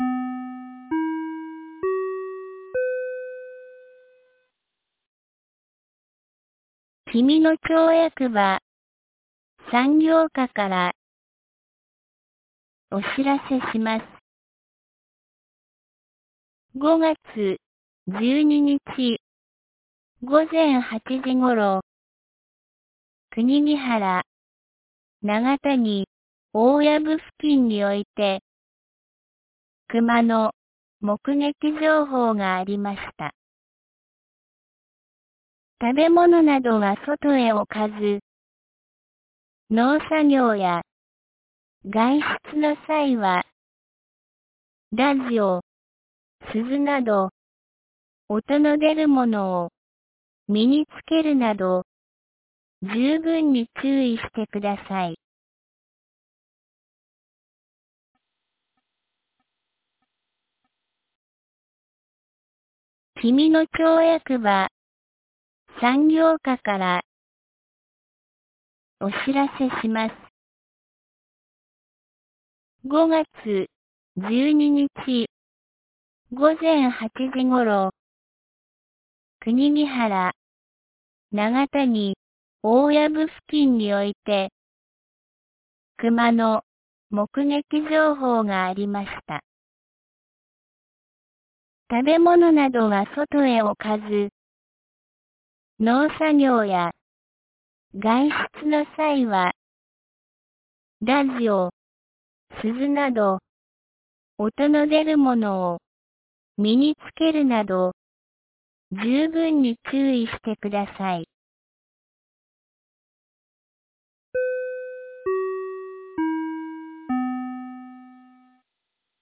2021年05月13日 12時32分に、紀美野町より東野上地区、志賀野地区へ放送がありました。